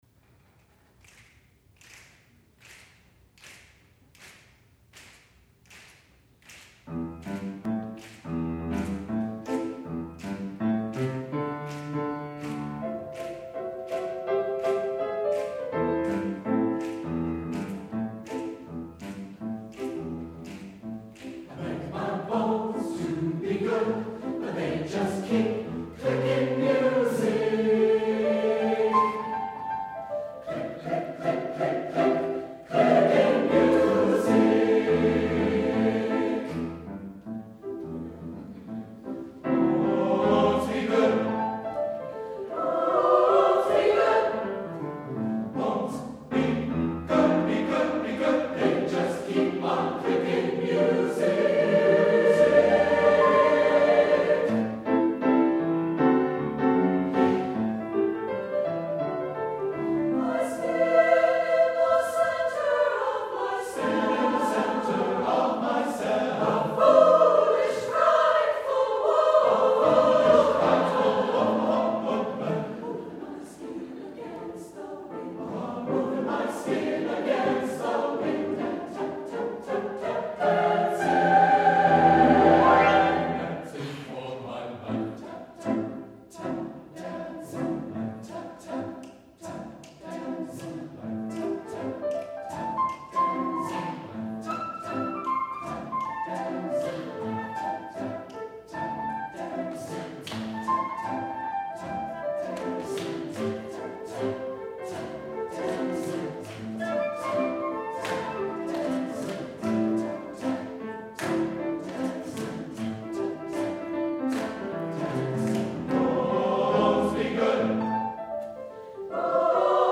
including added flute and hand-claps